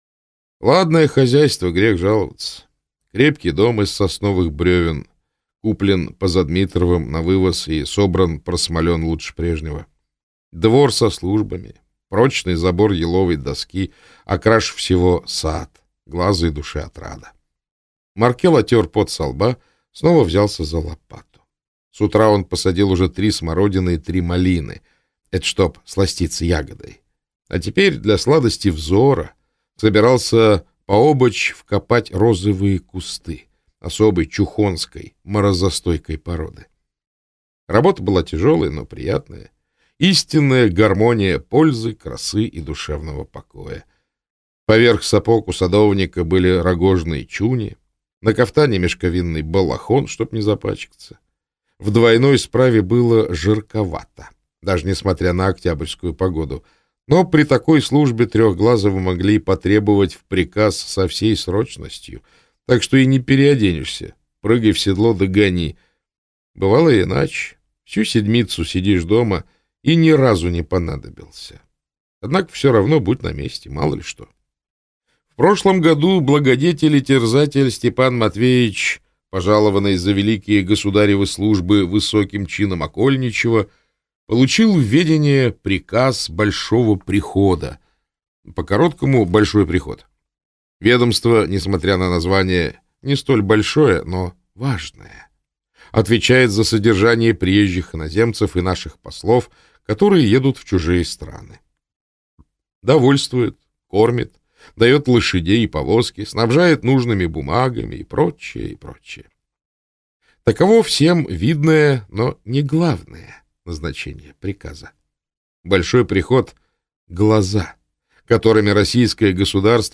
Аудиокнига Седмица Трехглазого (сборник) - купить, скачать и слушать онлайн | КнигоПоиск